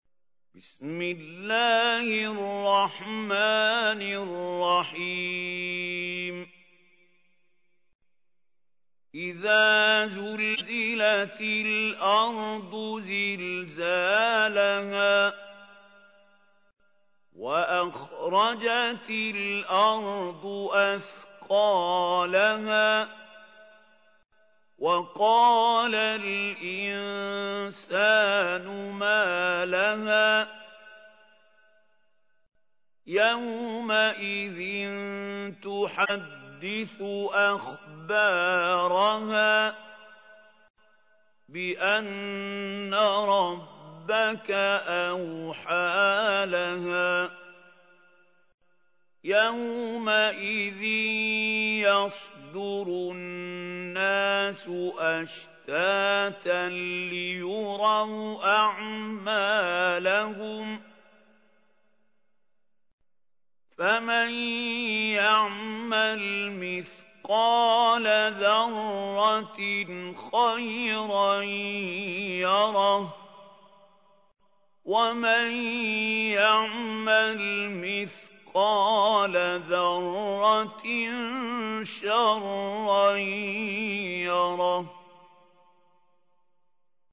تحميل : 99. سورة الزلزلة / القارئ محمود خليل الحصري / القرآن الكريم / موقع يا حسين